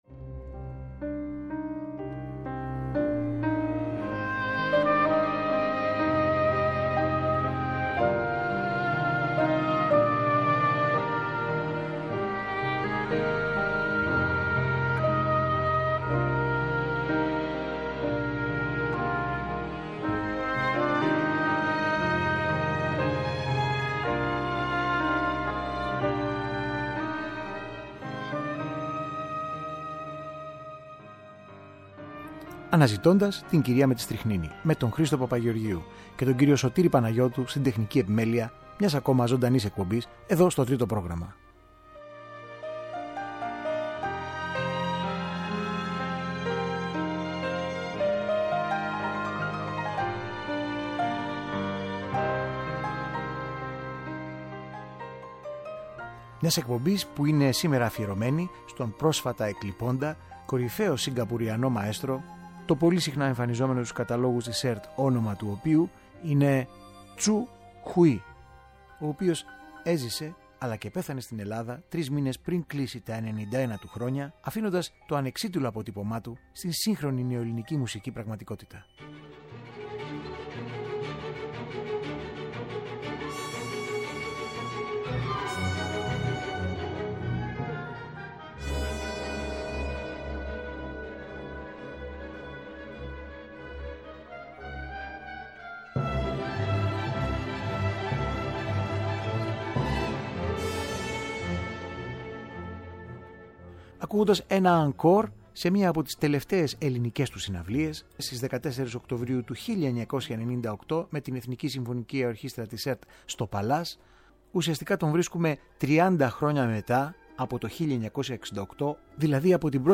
Με αφορμή την προ ημερών εκδημία του Σιγκαπουριανού αρχιμουσικού πατρουσιάζουμε μερικές από τις χαρακτηριστικές μουσικές στιγμές του στα 10 χρόνια που παρέμεινε ενεργός στην πατρίδα μας.